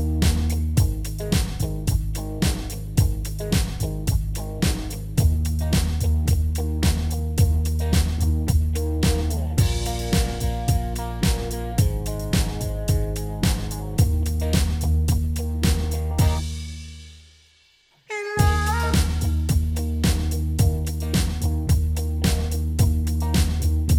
One Semitone Down Rock 4:11 Buy £1.50